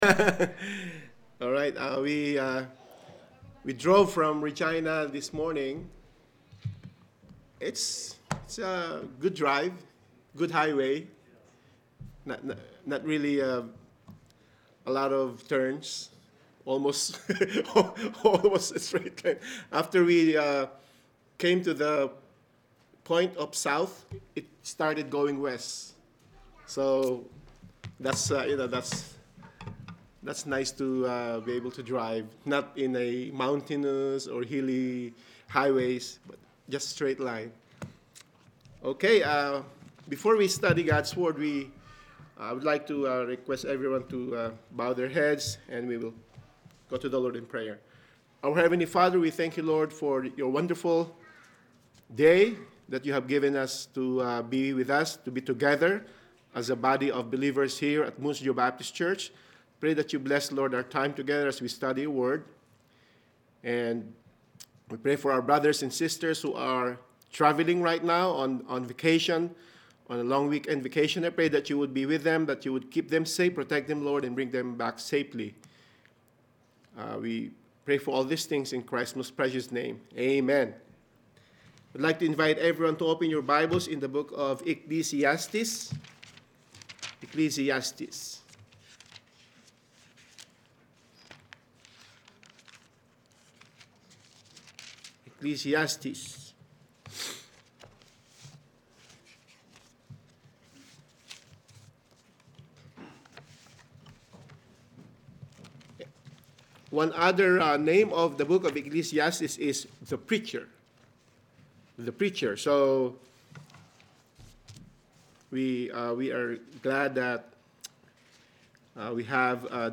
Adult Sunday School
Sermons